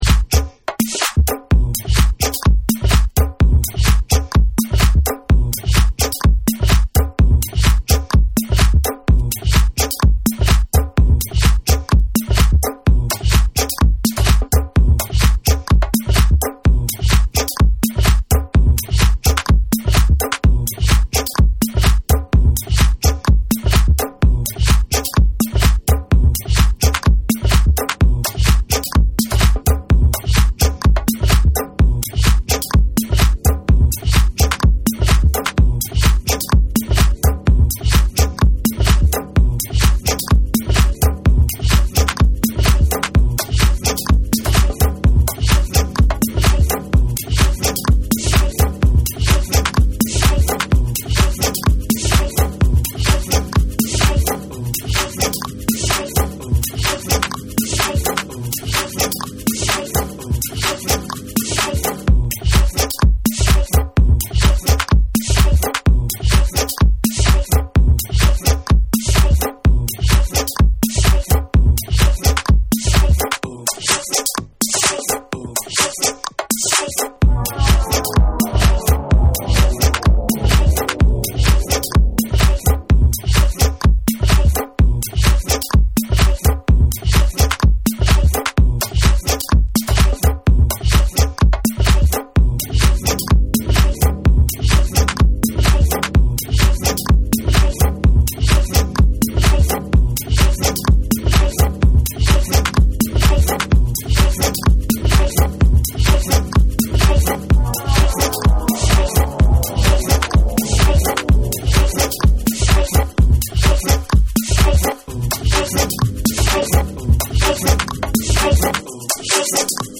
TECHNO & HOUSE / ORGANIC GROOVE